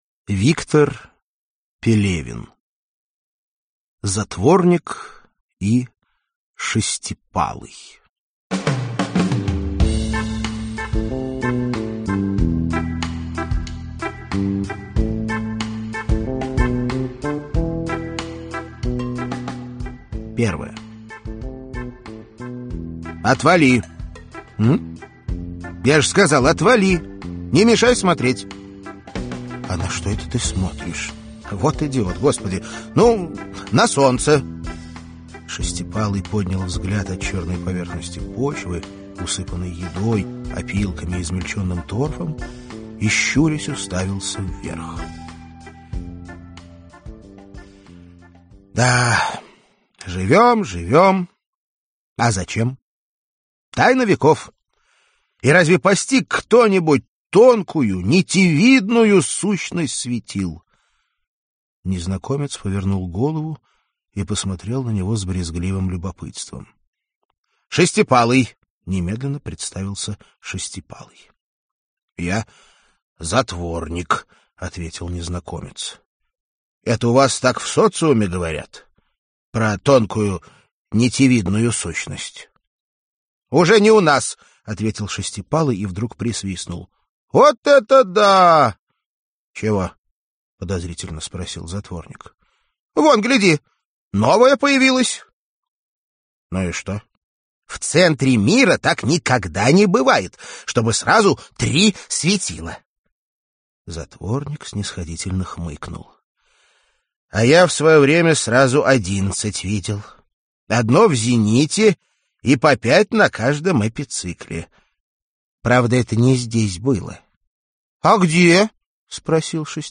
Аудиокнига Затворник и Шестипалый | Библиотека аудиокниг